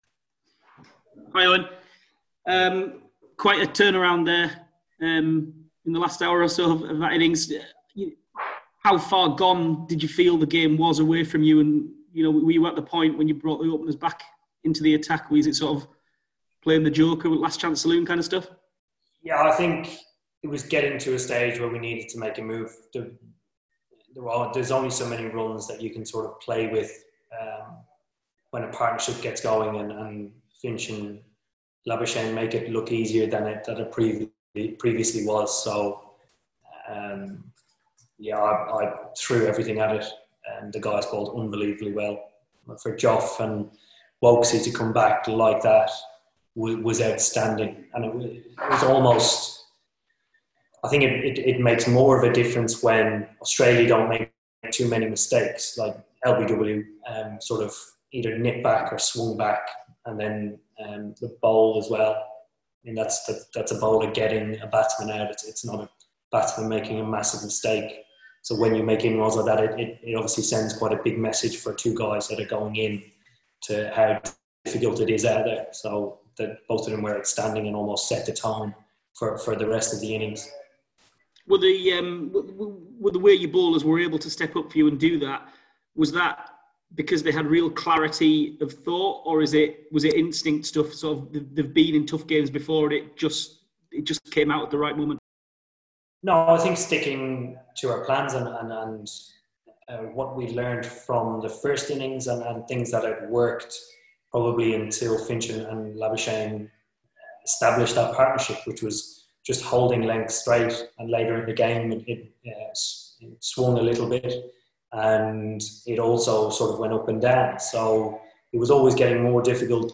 Eoin Morgan's virtual media conference after England beat Australia by 24 runs